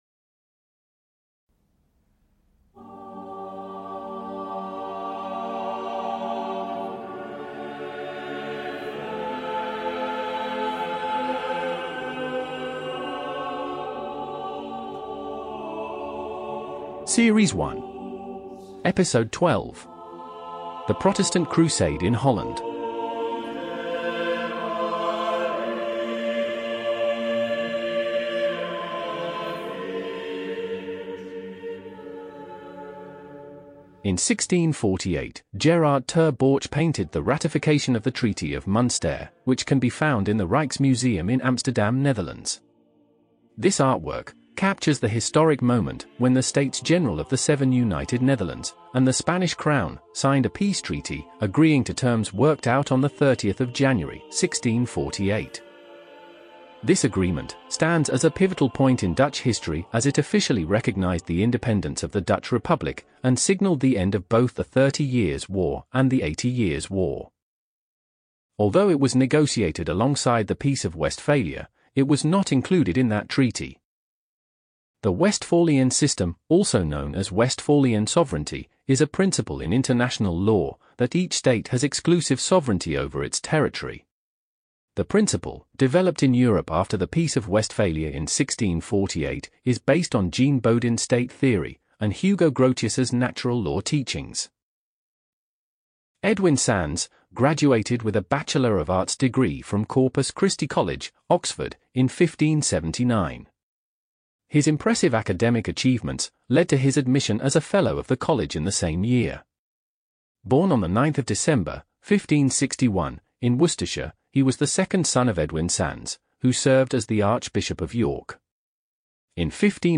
The music is Ave Verum Corpus – Composed by William Byrd and performed by The Sixteen.